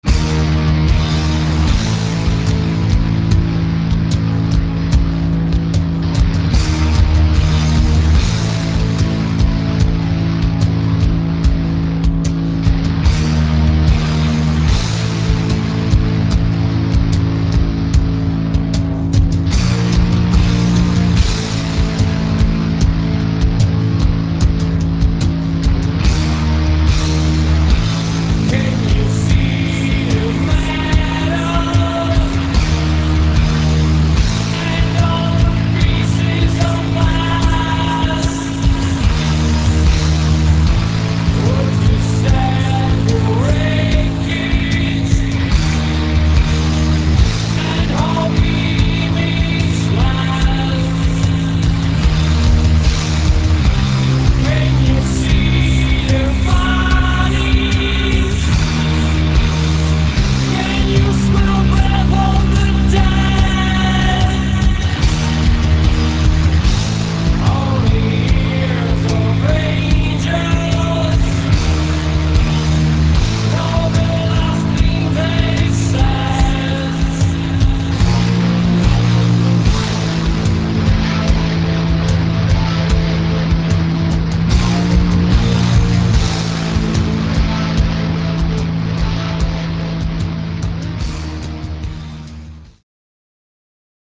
184 kB MONO